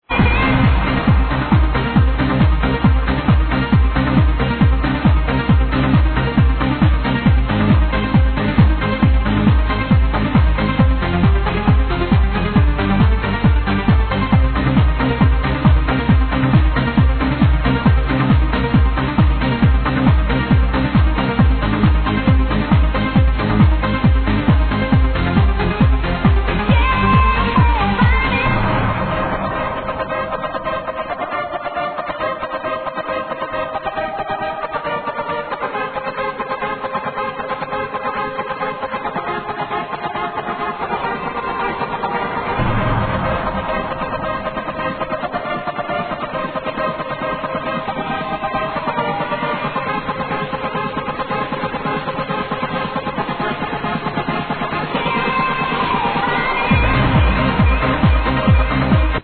hi need help identifying this trance track, thanks ppl!